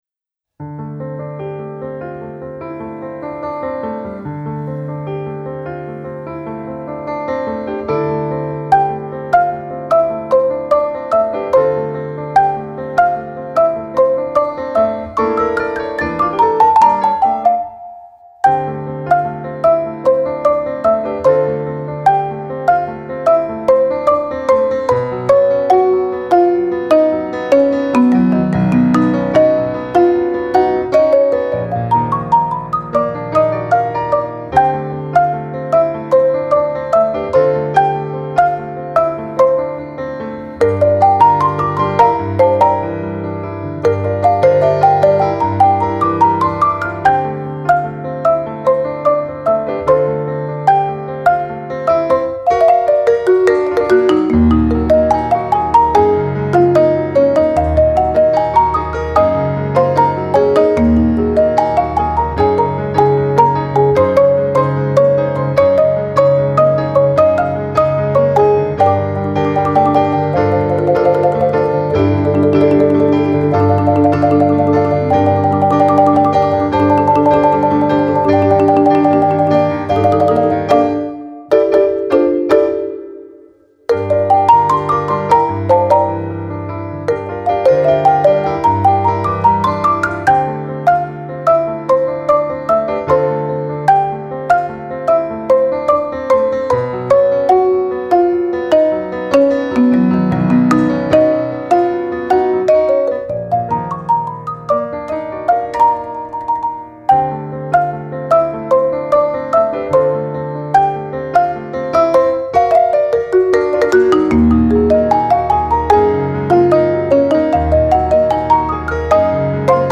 Mallets - Piano
Marimba - Piano